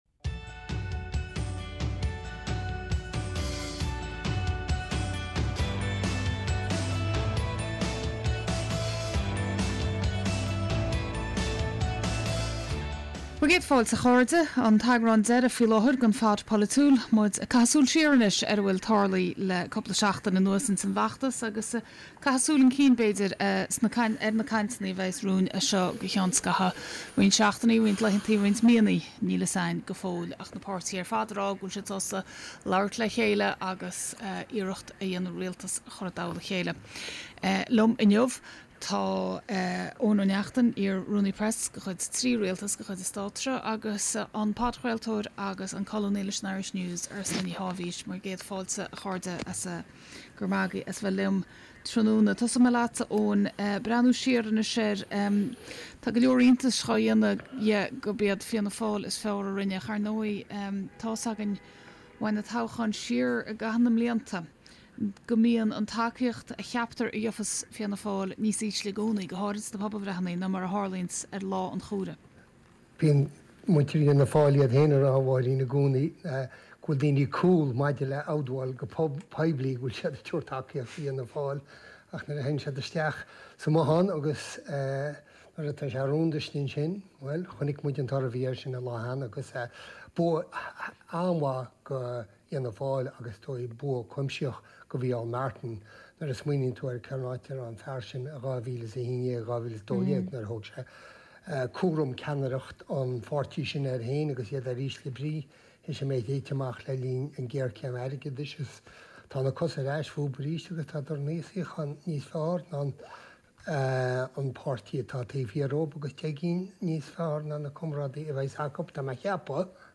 gach seachtain beidh aíonna agus tráchtairí léi sa stiúideo chun súil a chaitheamh ar an méid atá á rá.